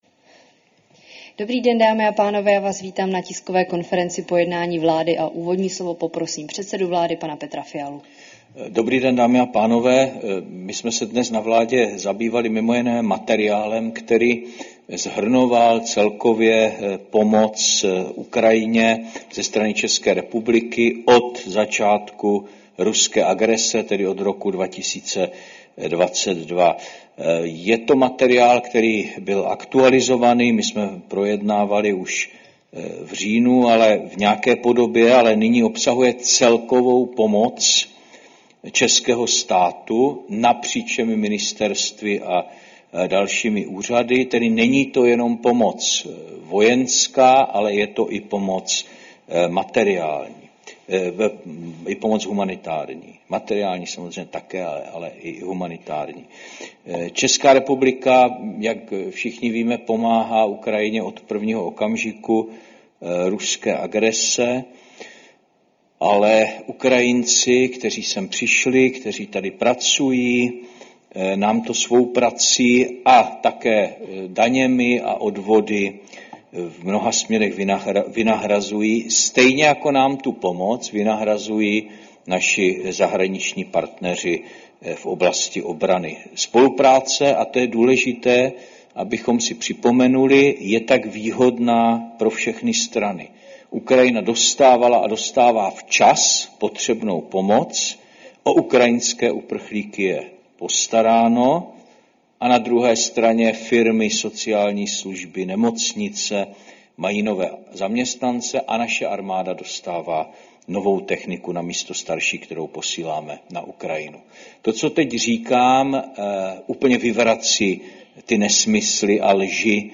Tisková konference po jednání vlády, 27. listopadu 2025